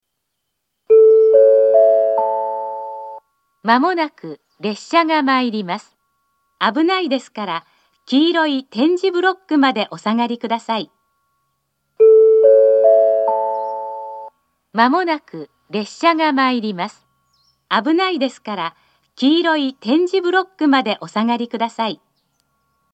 ２０２５年６月には放送装置が更新されているのが確認され、「黄色い点字ブロックまで」と言う放送になっています。
１番線接近放送 交換が無い場合上下ともこのホームを使用します。